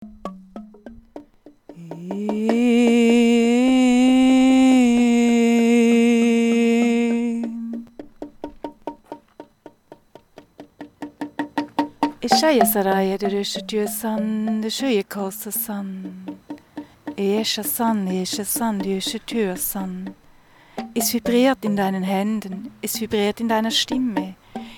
Tauche ein in die faszinierende Welt der Klangskulpturen.
Entdecke hörend das Klangspiel verschiedener Materialien.